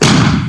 music are now 16bits mono instead of stereo